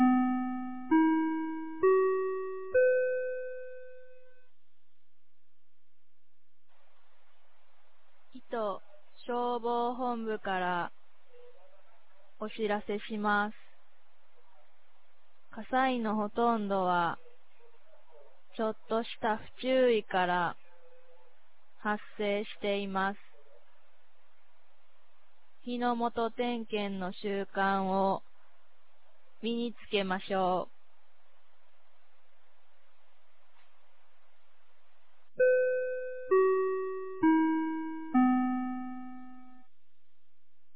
2025年05月26日 10時00分に、九度山町より全地区へ放送がありました。